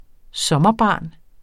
Udtale [ ˈsʌmʌˌbɑˀn ]